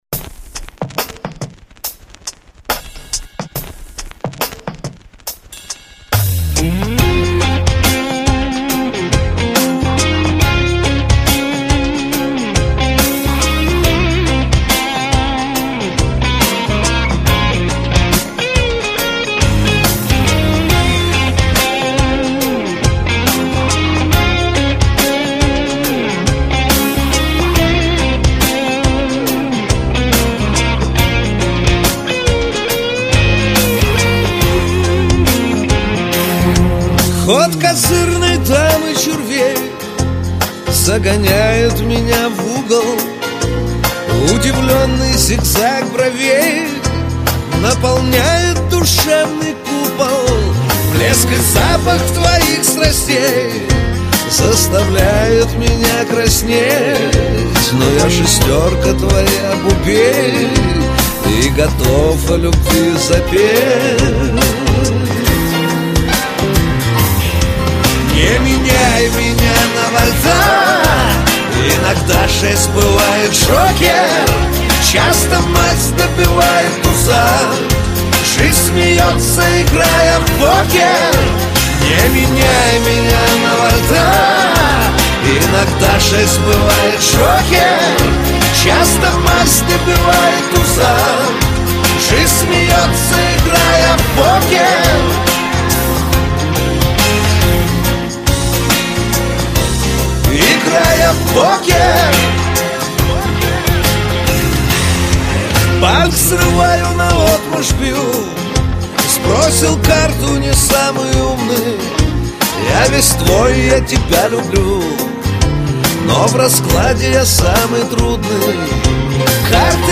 Категория: SHANSON